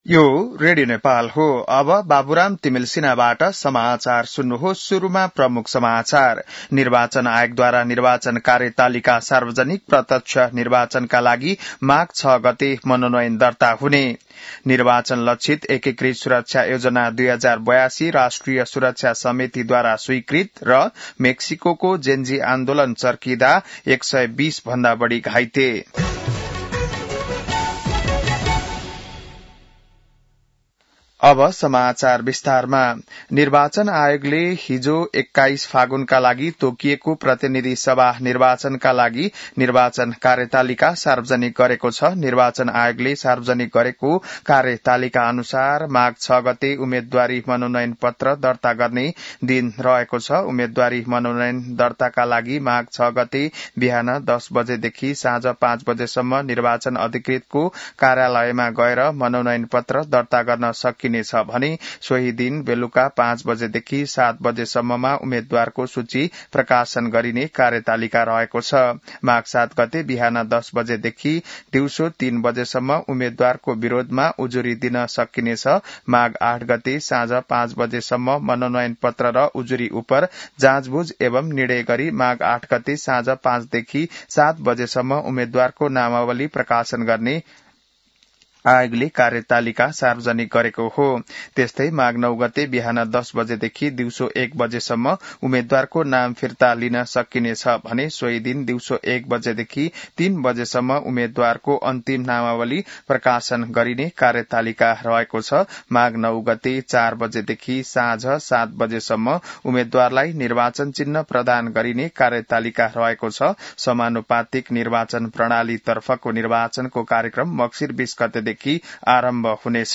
बिहान ९ बजेको नेपाली समाचार : १ मंसिर , २०८२